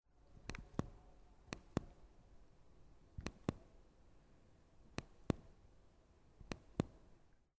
Mobile phone Nokia 8110
Turn down the volume
1316_Leiser_stellen.mp3